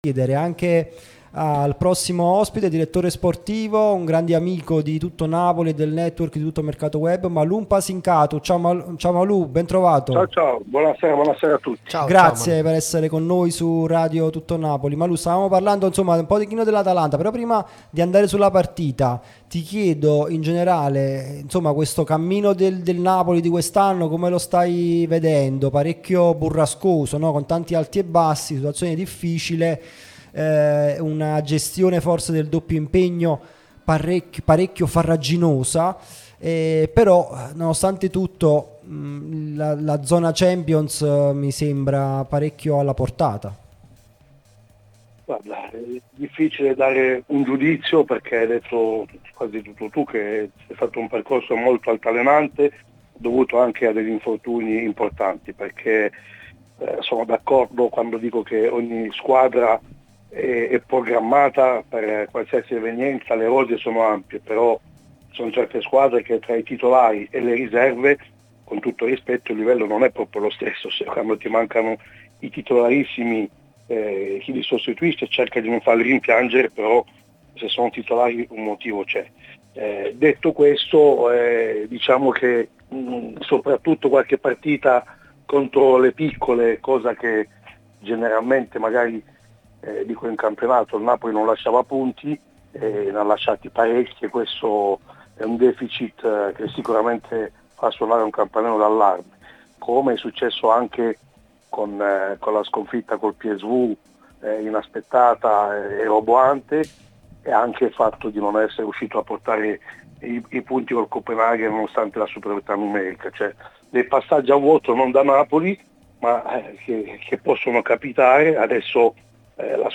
dirigente sportivo